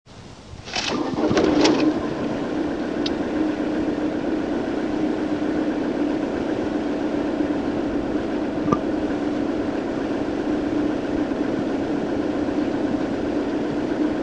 Recordings were made with all windows up and heater blower fan off.
WRMSTRT2.MP3 (99,657 bytes) - Another warm start, this time with the microphone in a slightly different position (still inside the car). As with the cold start, the click after starting (caused by the glow plug relay) indicates when the glow plugs shut off; the glow plug light never goes on during a warm start, but the plugs still operate for a few seconds while cranking to aid starting.